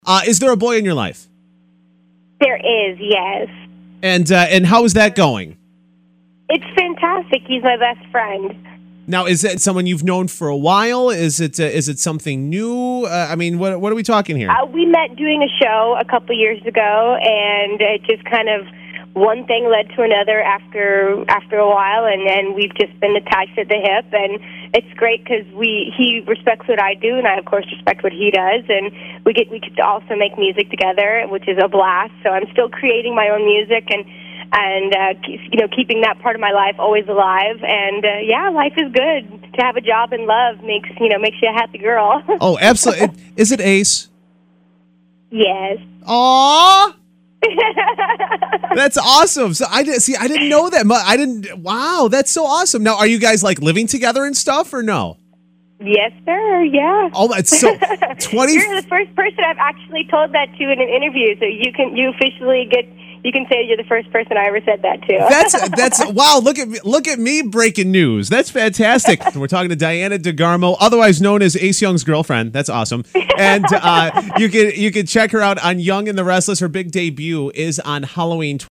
In a radio interview with 97.9 KISS FM, Diana reveals that the co-stars eventually became a romantic couple.
Listen to Diana reveal her relationship in an interview with KISS-FM 97.9.